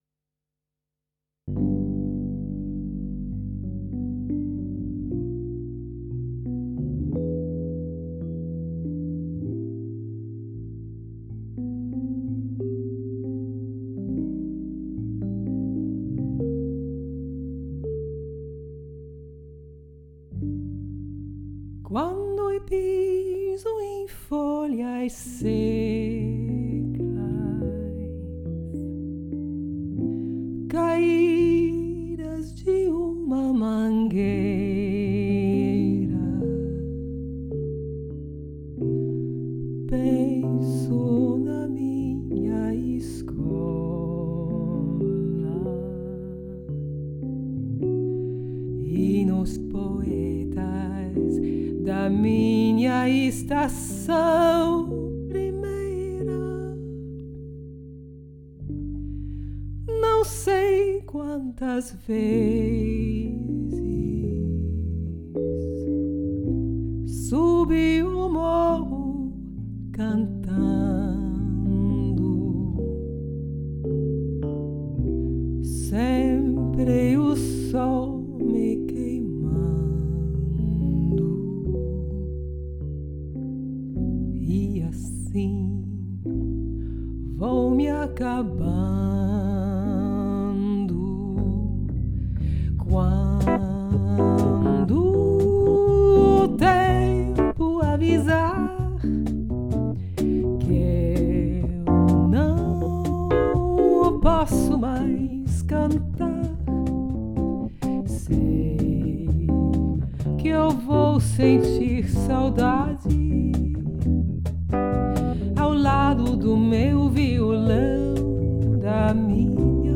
Hier gibt einige Aufnahmen aus Konzert und Studio: